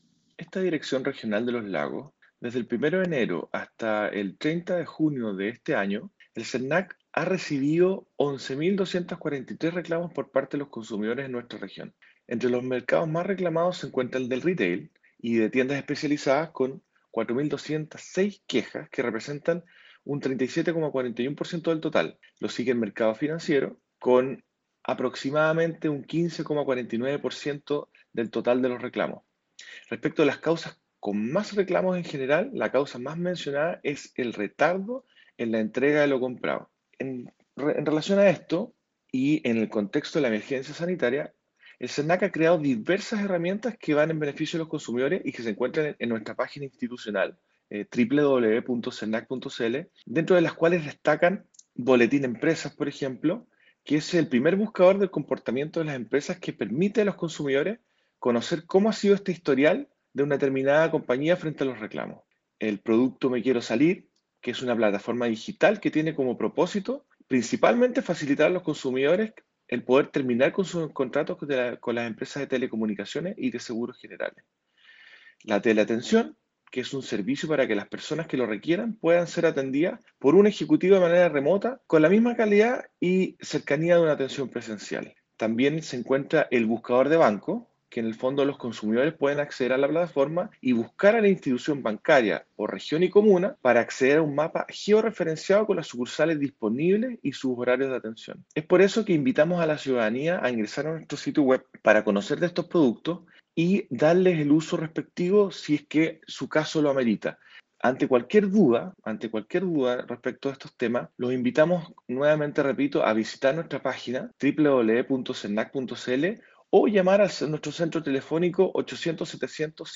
Ir a Audio Director del SERNAC de Los Lagos, Osvaldo Emhart